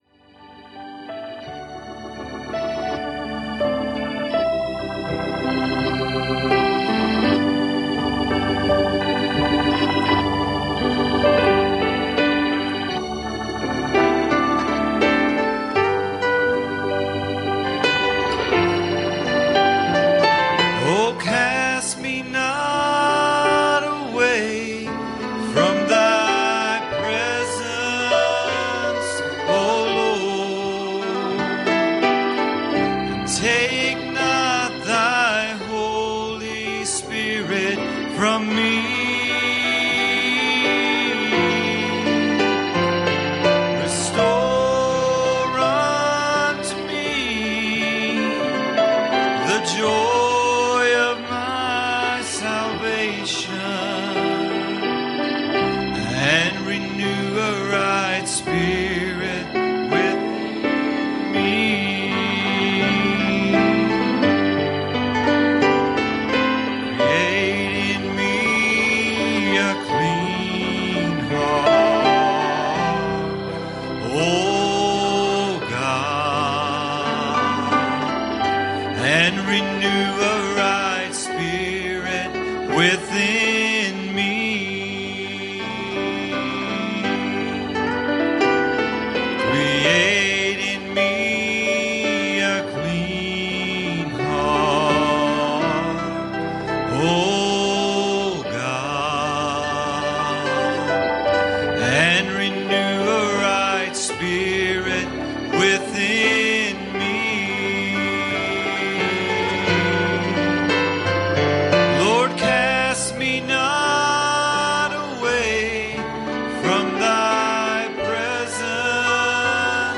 Passage: Matthew 5:9 Service Type: Sunday Evening